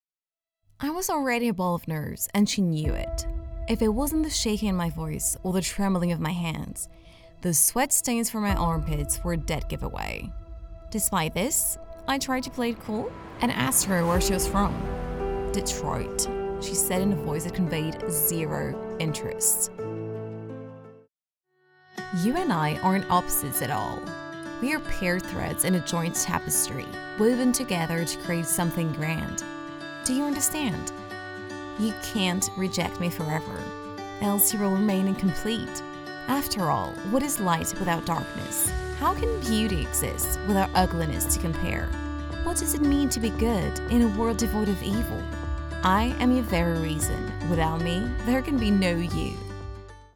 I work on Pro Tools First and Adobe Audition with a Rode NT1-A mic.
standard us | natural
ENG_American_accent_reel.mp3